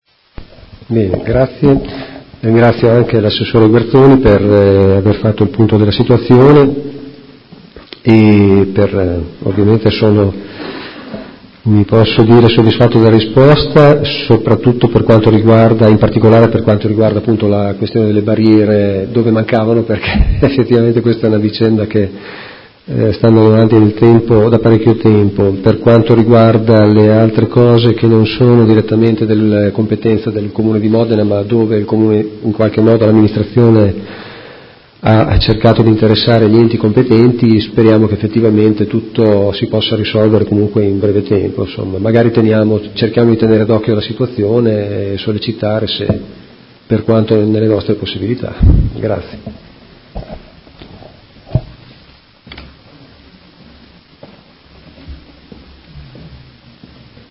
Seduta del 10/01/2019 Replica a risposta Interrogazione dei Consiglieri Malferrari e Stella (Art1-MDP/Per Me Modena) avente per oggetto: Vecchi e nuovi disagi per i residenti di Stradello Capitani